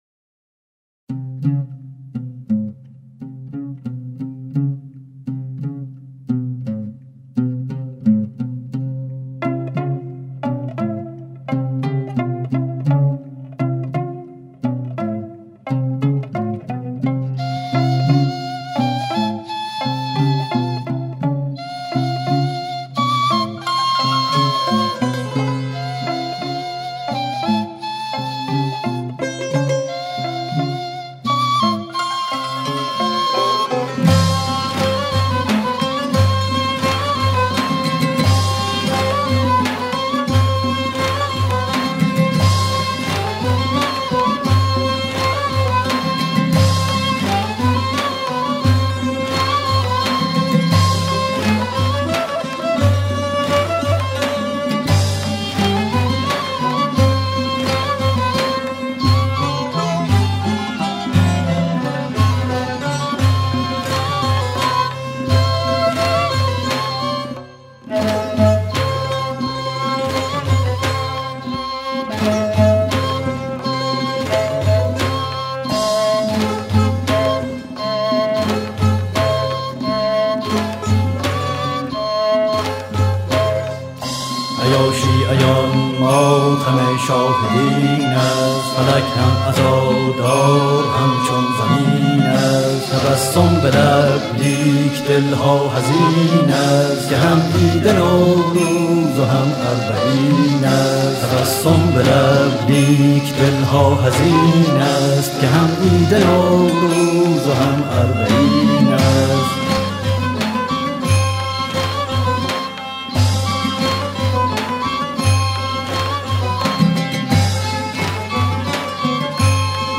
این قطعه در دستگاه چهارگاه ساخته شده است.
کمانچه
نی
سنتور
تار
عود و دف
تنبک